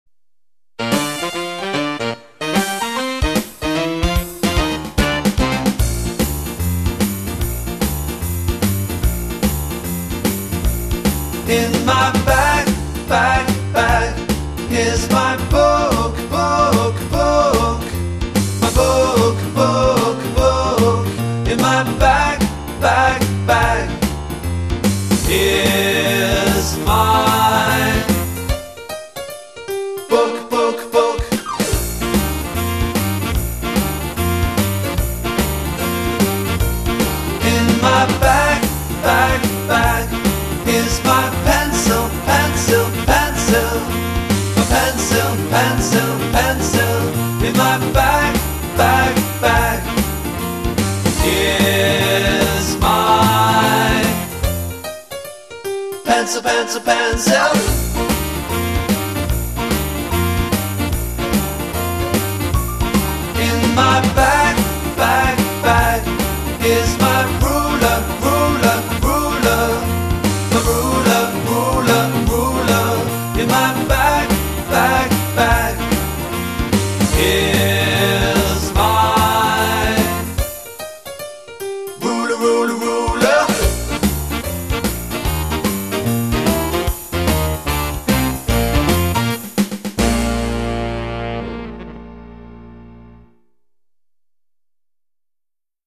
In my bag Posted in 1A , 1B , General , Year 2012-13 | Tagged classroom objects/objectes de la classe , song/cançó | Leave a reply